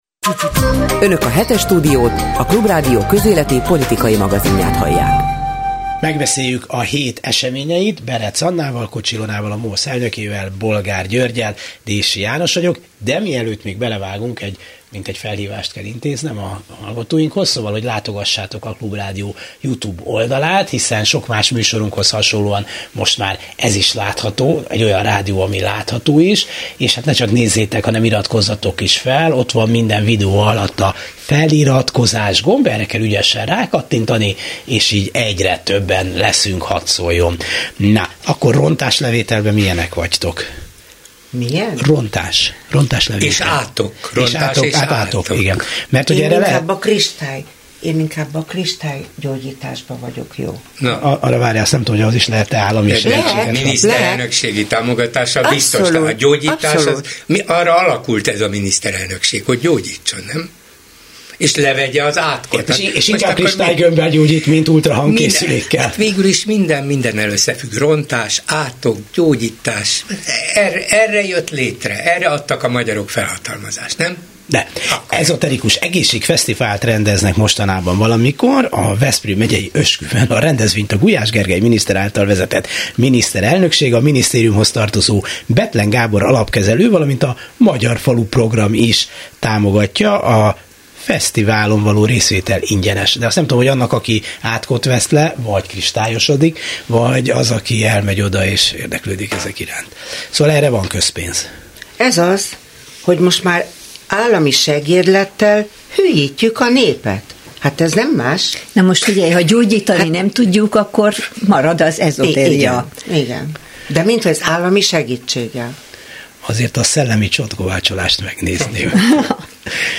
Hétről hétre a legfontosabb történéseket vitatjuk meg újságíró kollégákkal a Klubrádió stúdiójában.